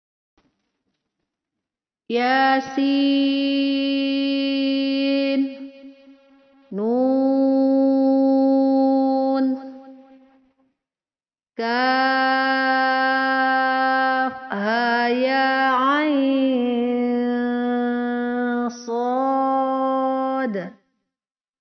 Panjang 6 harokat sama dengan 3x ayun suara.